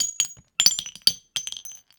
weapon_ammo_drop_15.wav